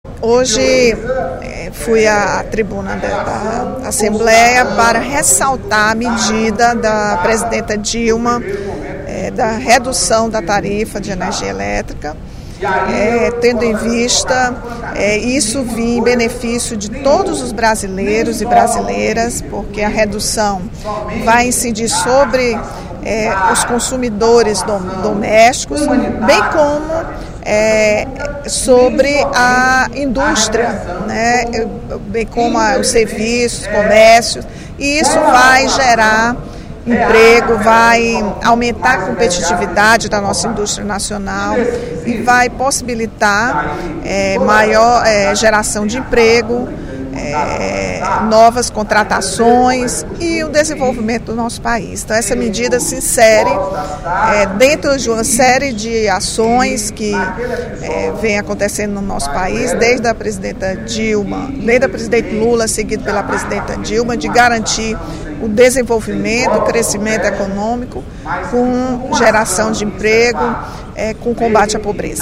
A deputada Rachel Marques (PT) comemorou, durante pronunciamento no primeiro expediente da sessão plenária desta quarta-feira (06/02), a redução da tarifa de energia anunciada pelo Governo Federal.